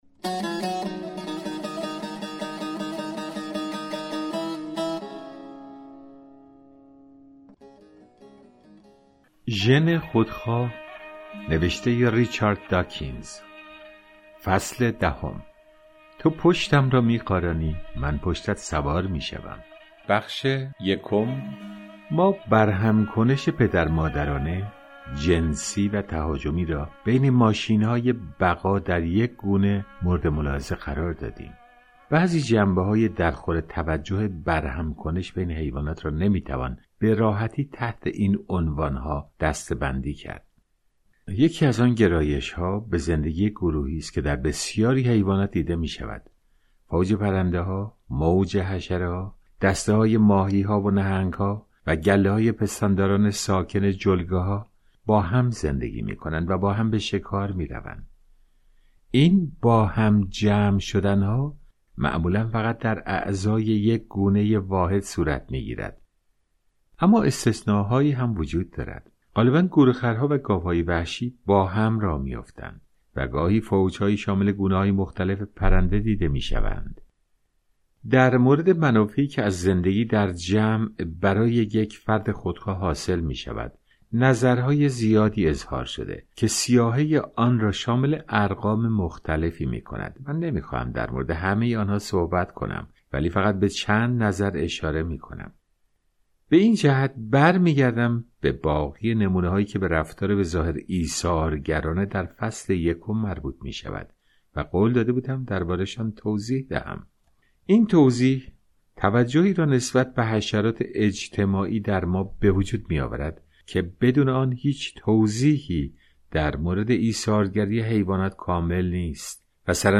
کتاب صوتی ژن خودخواه (10)